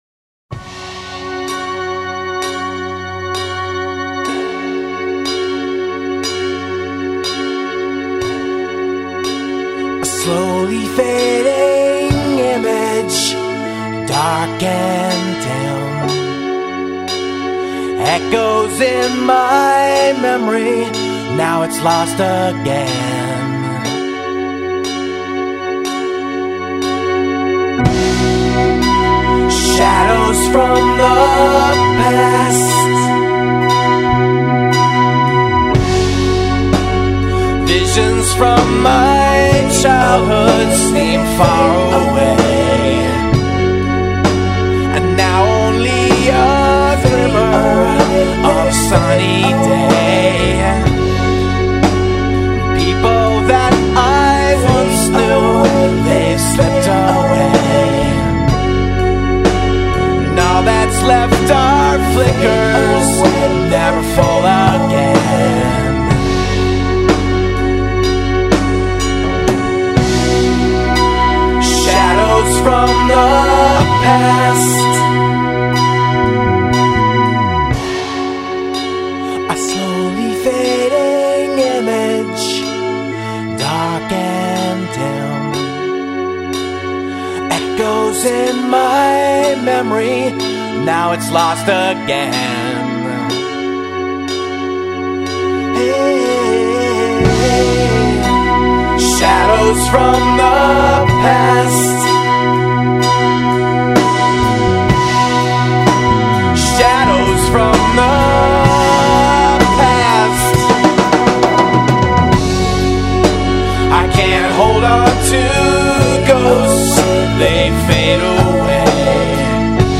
Polyrhythmic, eclectic, very modern 'art funk-rock' music.